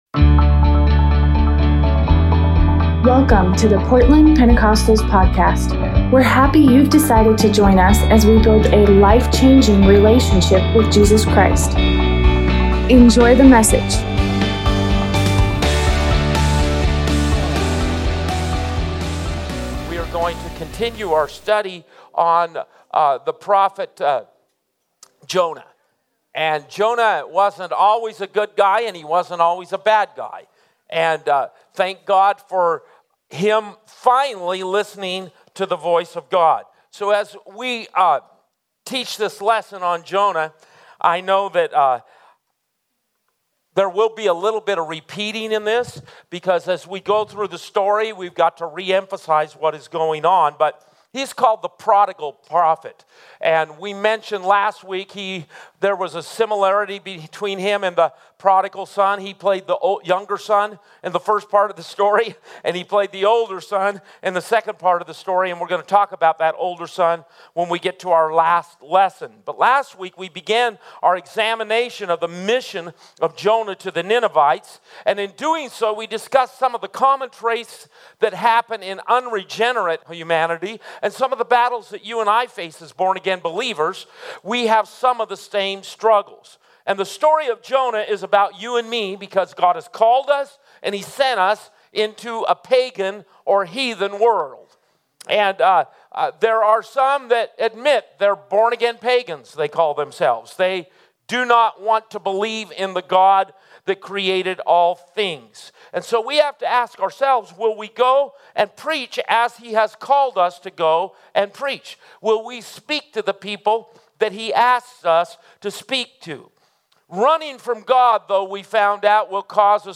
Tuesday Night Bible Study By